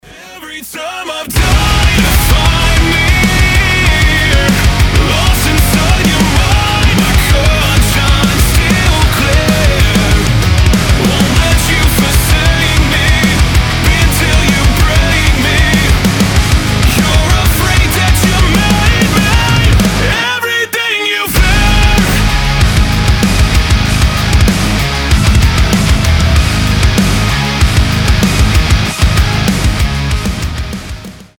громкие
мощные
Alternative Metal
Post-Hardcore